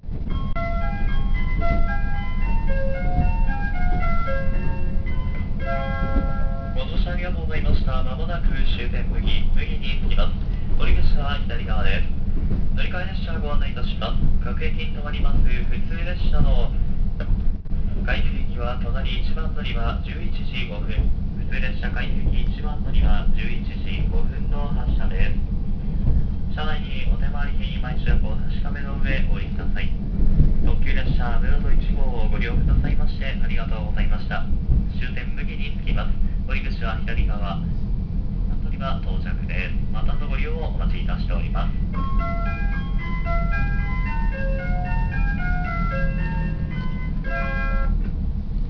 〜車両の音〜
・キハ185系車内放送
【特急むろと1号】牟岐到着時（48秒：266KB）
自動放送はついていませんが車内チャイムは存在しており、JR四国の汎用チャイムのうち、8000系等に搭載されている音色で且つ音程がやや高いタイプが使用されています。